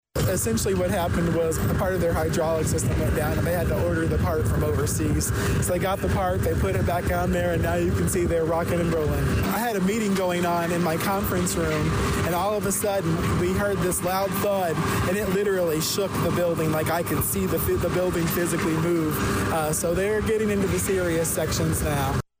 Mayor Rickey Williams, Jr was outside watching for a while; and he told us that when he was inside his office, he could certainly tell what was happening next door.